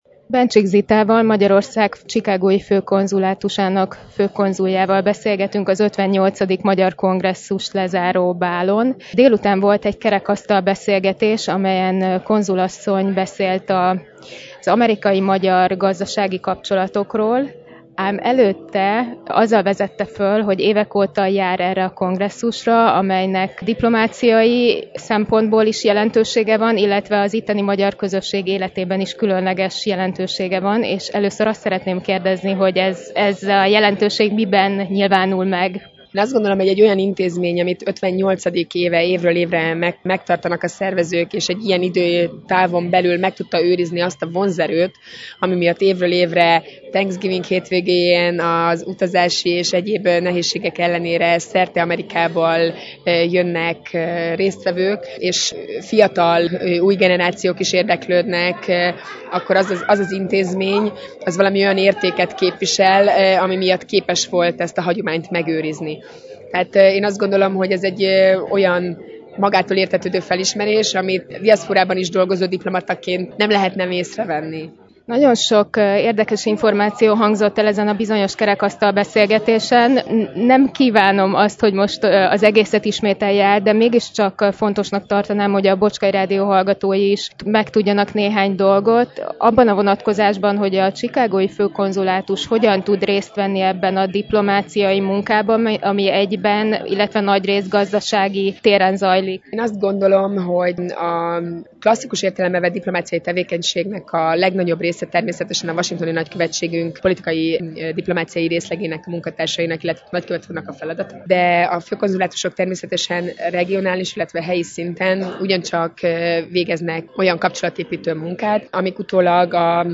Dr. Bencsik Zita főkonzul asszonnyal